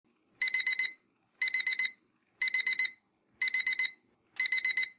alarm_1.mp3